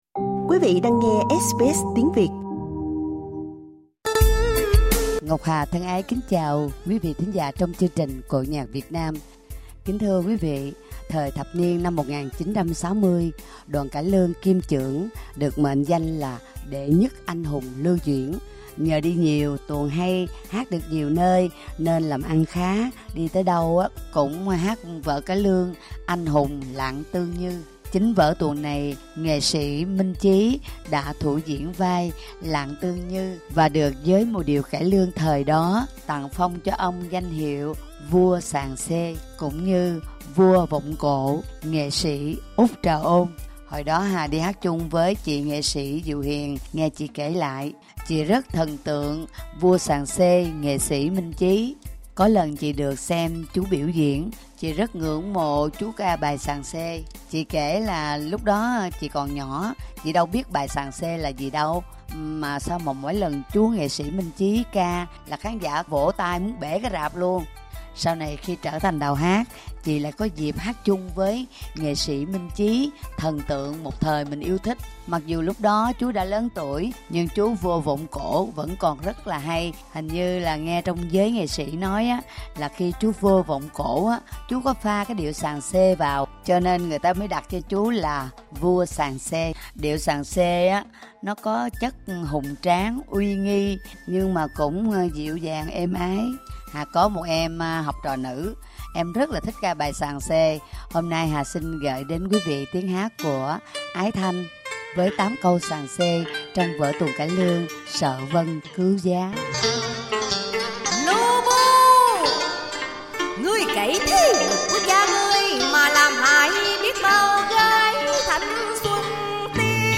Xin mời quý vị nghe 8 câu xàng xê, 1 lớp Xuân tình